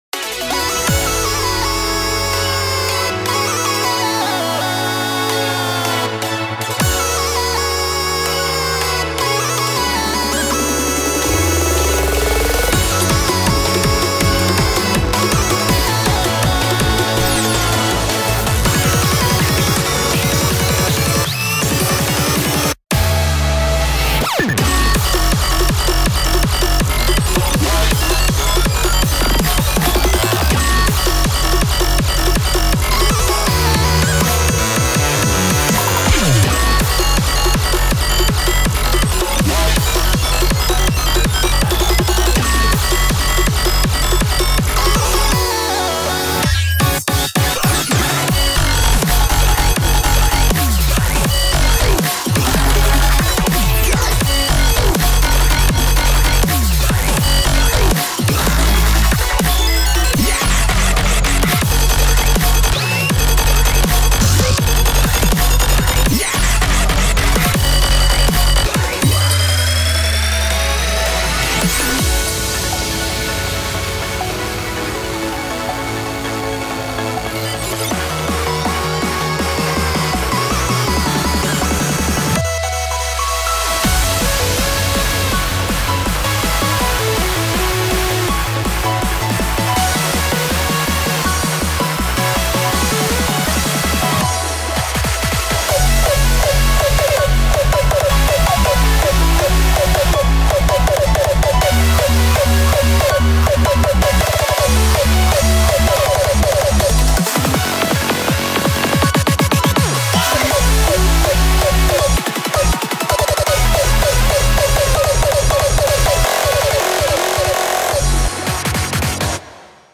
BPM81-162
Audio QualityPerfect (High Quality)